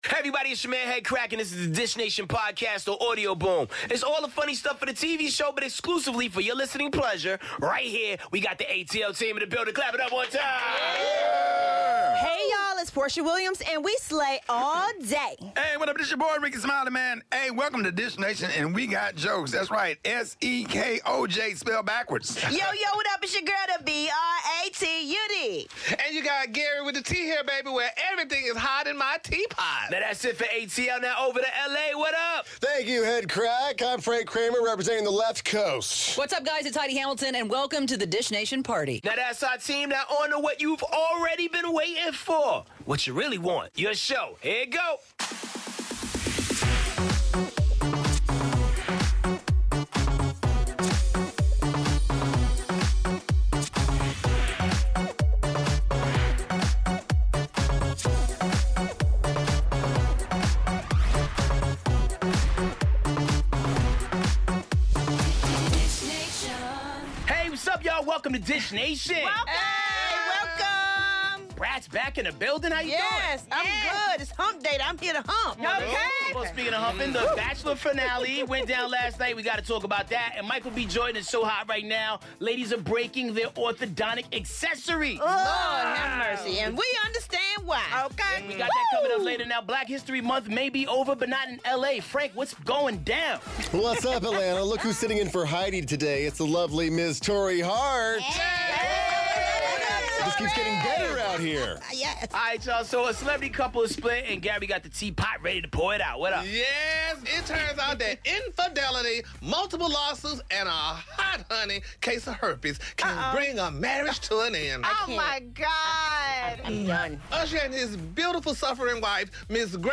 Guest co-host Torrei Hart. Usher announces separation and 'The Bachelor' finale gives us a new engagement and a new 'Bachelorette.' Plus all the latest on Meghan Markle, Katt Williams, Sharon Osbourne and much more!